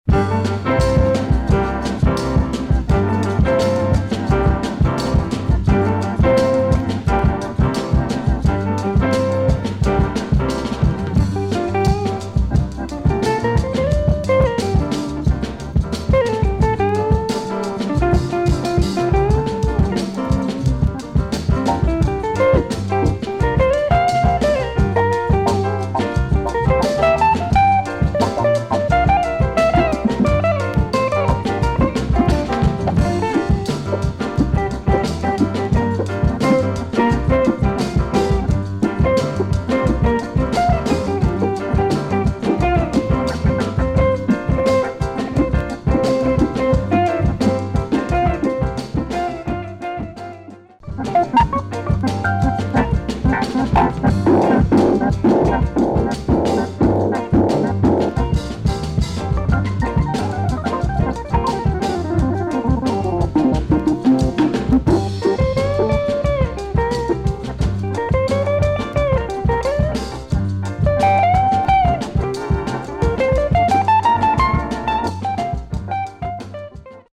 Cool Italian groovy jazz sound with light breaks.